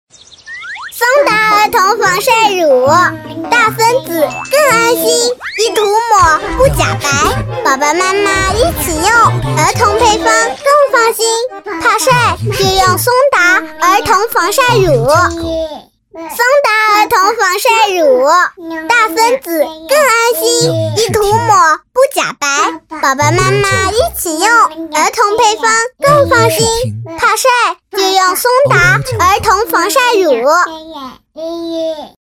女B32-真童TVC -松达
女B32-大气质感 可爱
女B32-真童TVC -松达.mp3